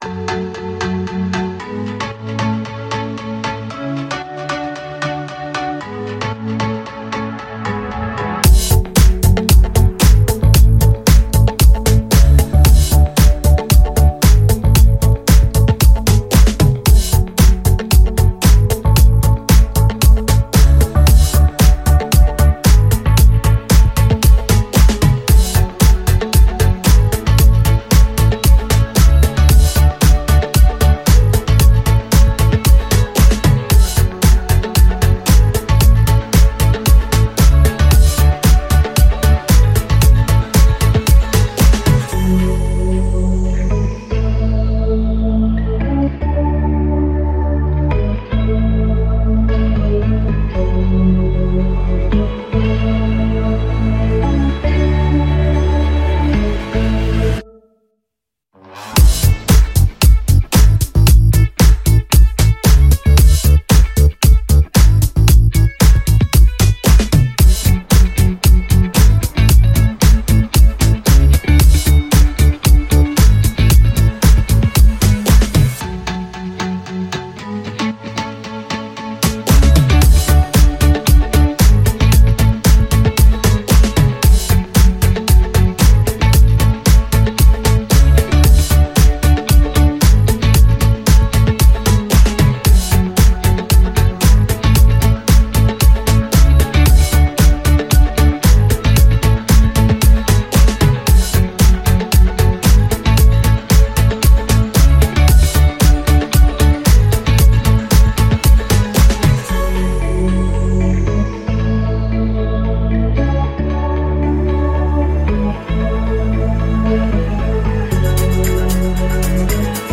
Прикольный инструментальчик